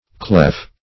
clef - definition of clef - synonyms, pronunciation, spelling from Free Dictionary
Clef \Clef\ (kl[e^]f; 277), n. [F. clef key, a key in music, fr.